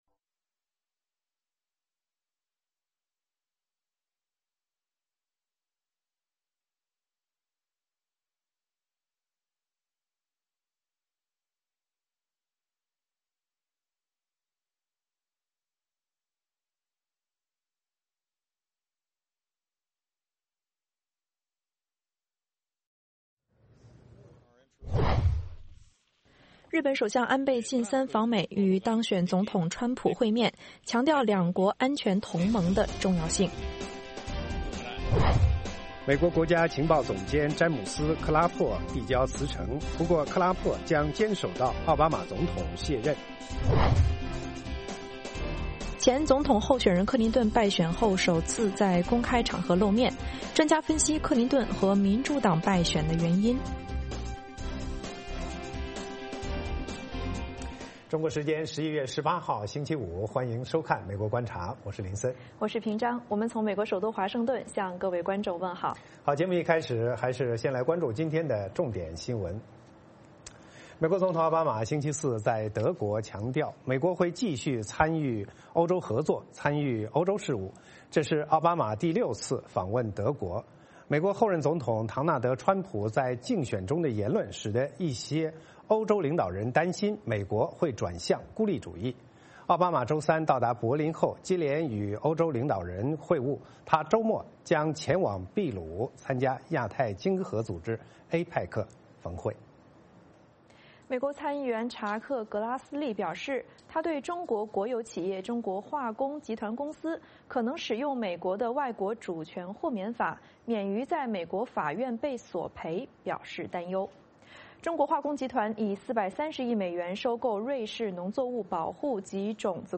美国之音中文广播于北京时间晚上8－9点重播《VOA卫视》节目(电视、广播同步播出)。
“VOA卫视 美国观察”掌握美国最重要的消息，深入解读美国选举，政治，经济，外交，人文，美中关系等全方位话题。节目邀请重量级嘉宾参与讨论。